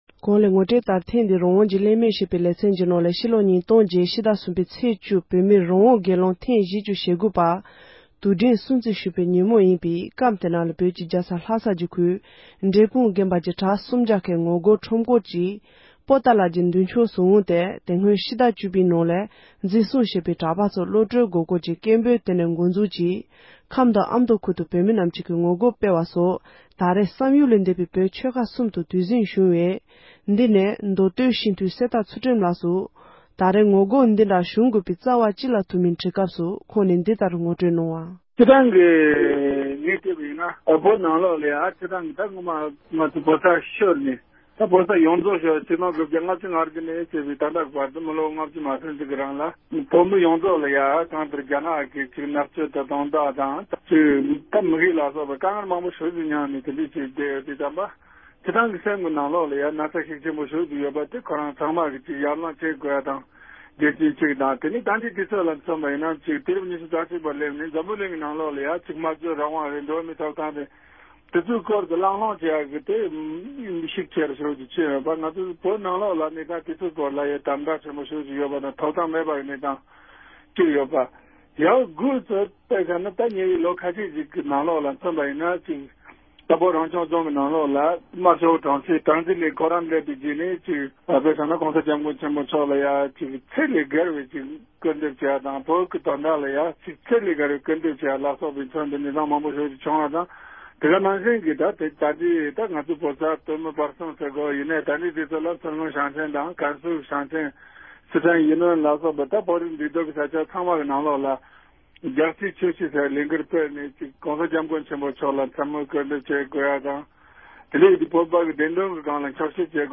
བོད་མི་མདོ་སྟོད་སྤྱི་འཐུས་གསེར་རྟ་ཚུལ་ཁྲིམས་ལགས་དང་གླེང་བ།